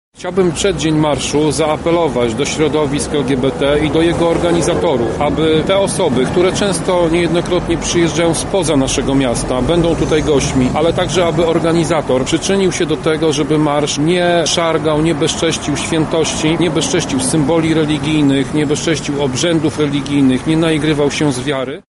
-mówi radny Prawa i Sprawiedliwości Tomasz Pitucha.